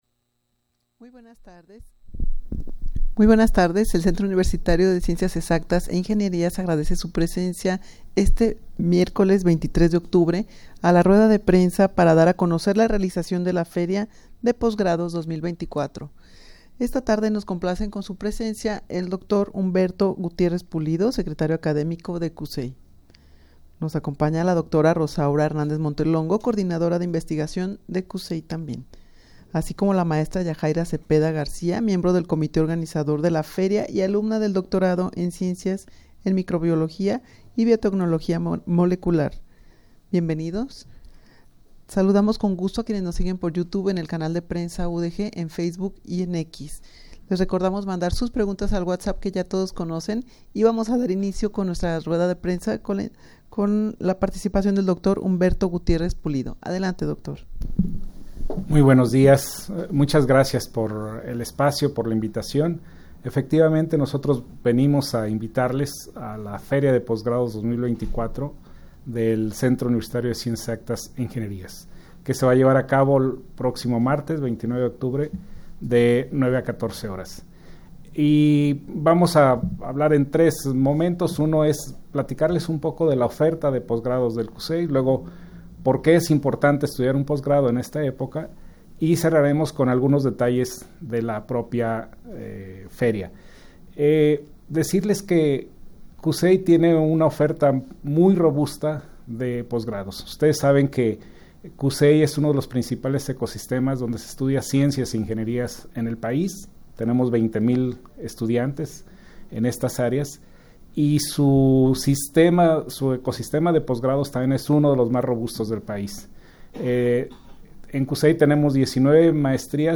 Audio de la Rueda de Prensa
rueda-de-prensa-para-dar-a-conocer-la-realizacion-de-la-feria-de-posgrados-2024.mp3